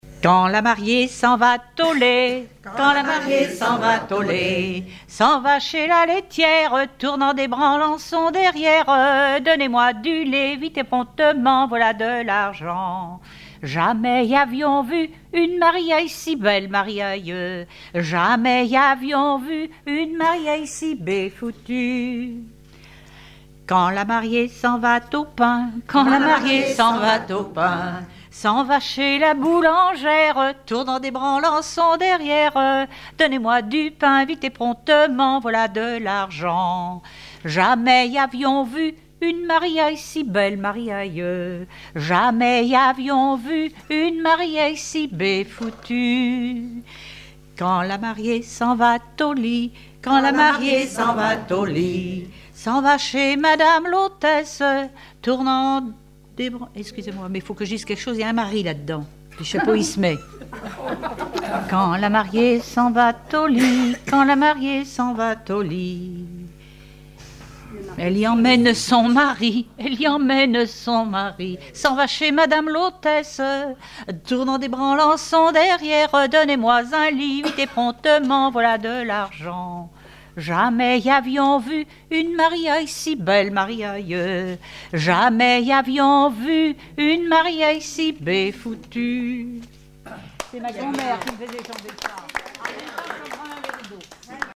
Genre énumérative
Répertoire de chansons populaires et traditionnelles
Pièce musicale inédite